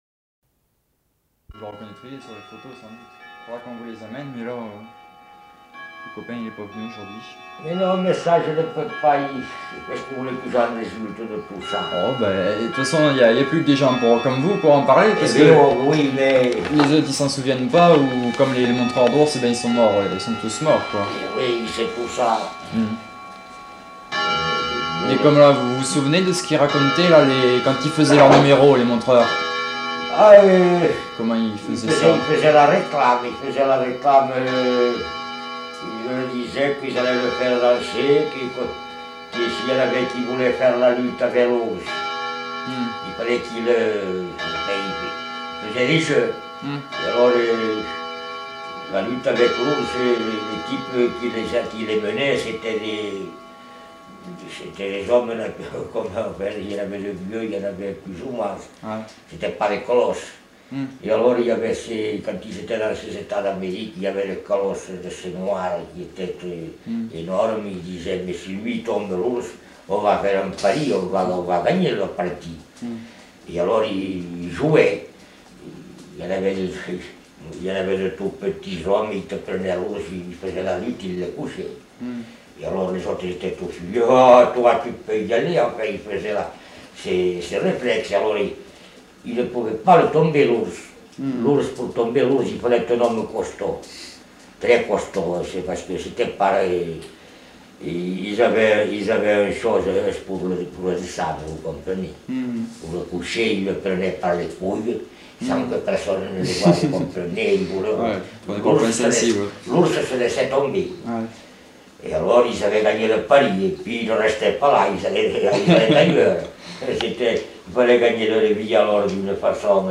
Aire culturelle : Couserans
Lieu : Cominac (lieu-dit)
Genre : témoignage thématique